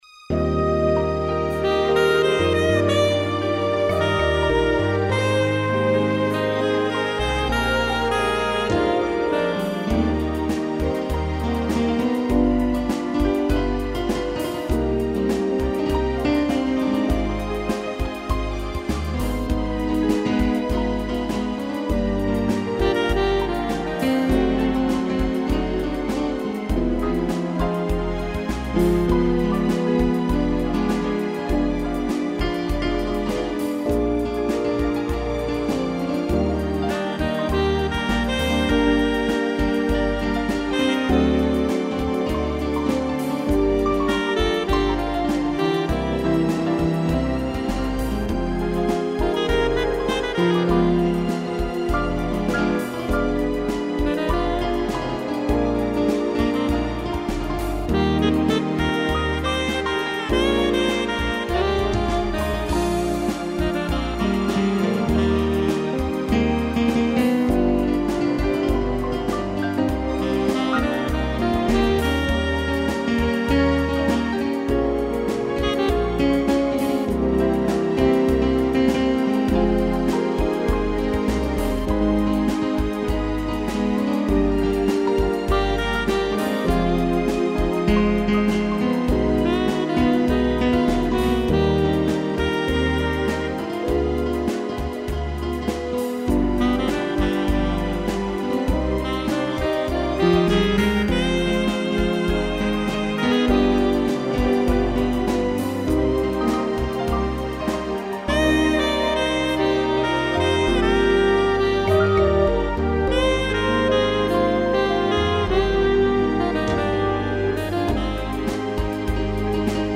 piano, cello, violino e sax
instrumental